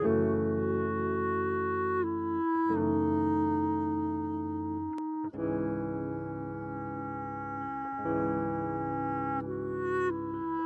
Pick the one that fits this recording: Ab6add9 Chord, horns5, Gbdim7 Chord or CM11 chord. horns5